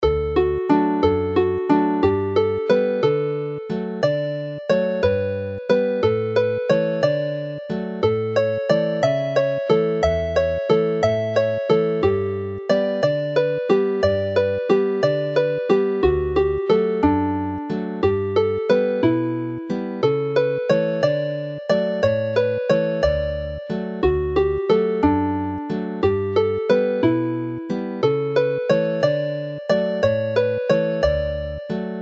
Play the tune slowly